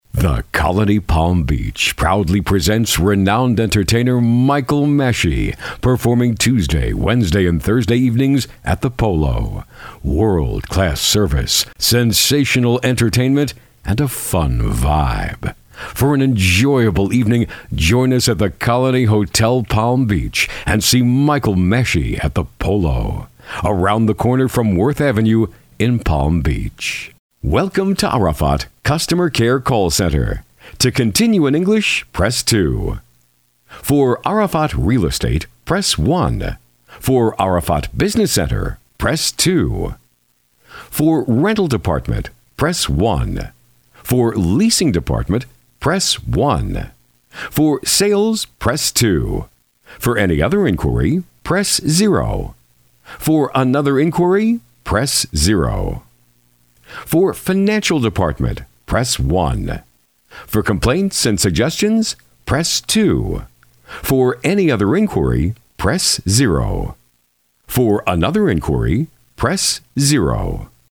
Voiceover Demos — The Audio Planet